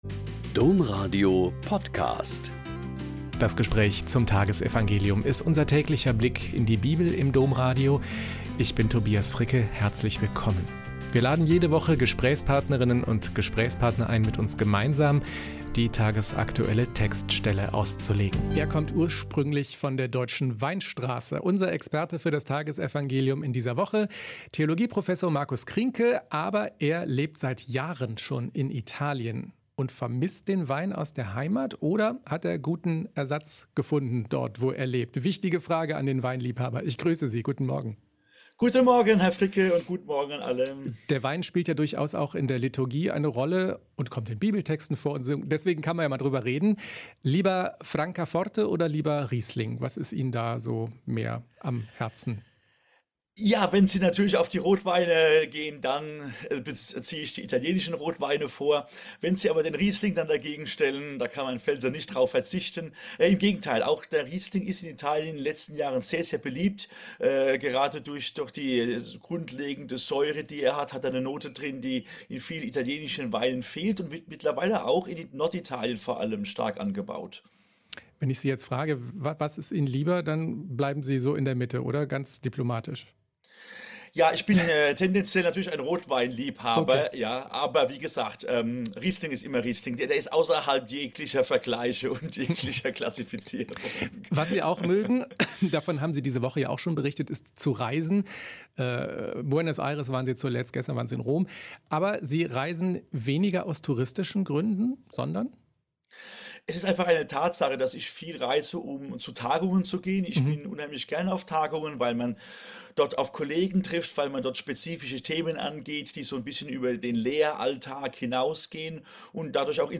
Joh 15,9-11- Gespräch